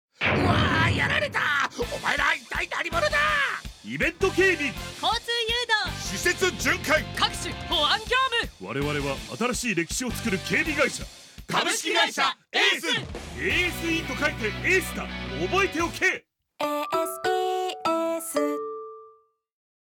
●もういっちょラジオCM＆サウンドロゴ！
こちらも台本の作成とサウンドロゴに携わらせていただきました。
こちら、BGMは台本に合わせてFMノースウェーブさまがチョイス。